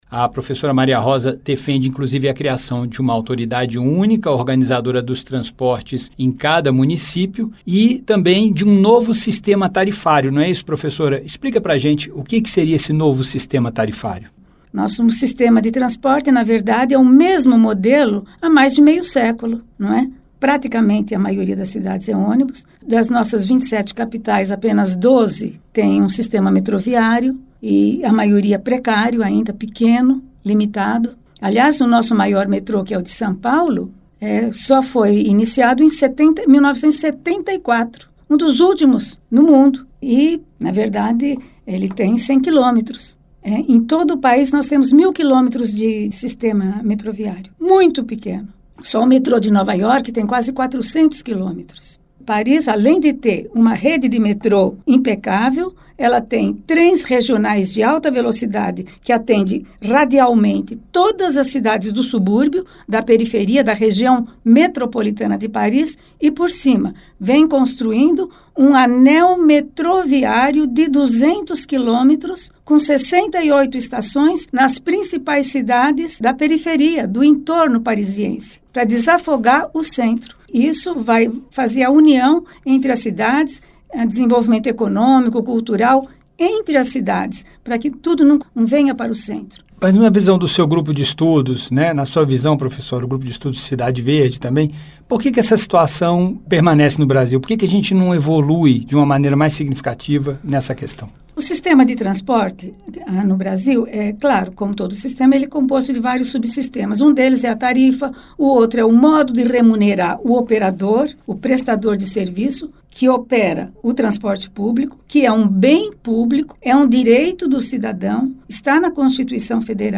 Professora defende melhorias na administração da mobilidade urbana